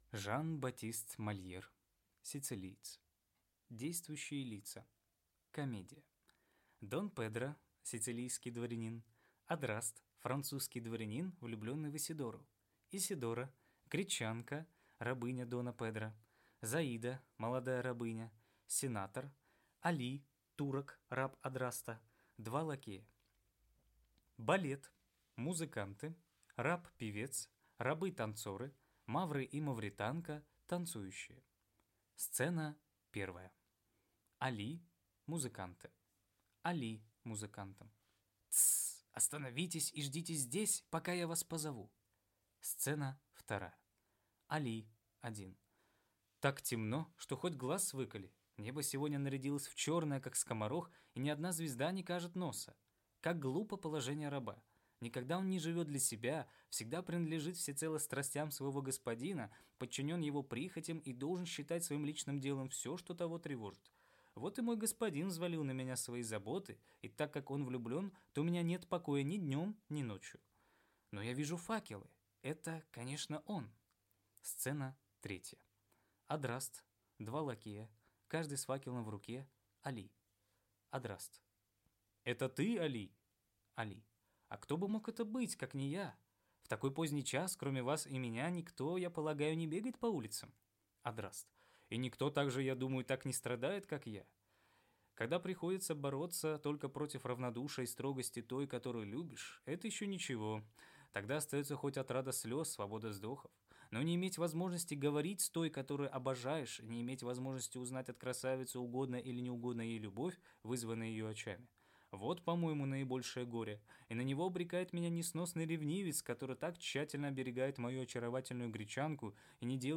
Аудиокнига Сицилиец